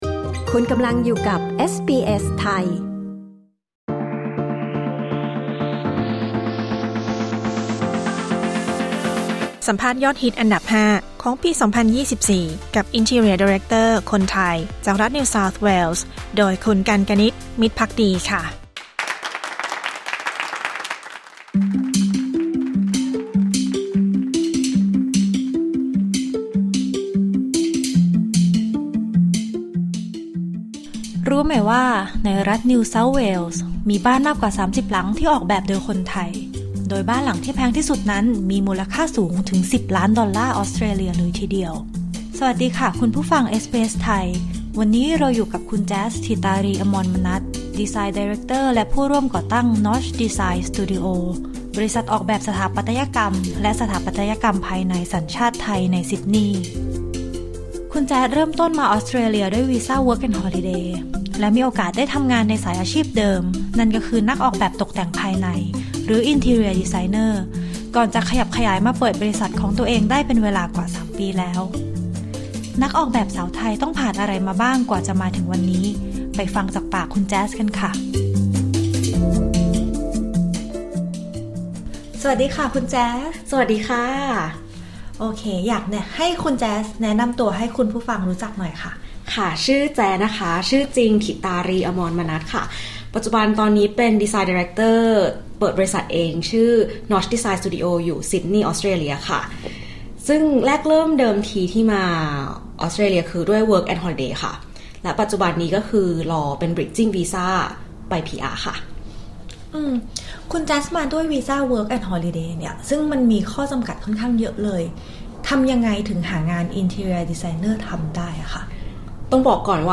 สัมภาษณ์ยอดฮิตอันดับ 5 Interior Director คนไทยในรัฐนิวเซาท์เวลส์ Credit: SBS Thai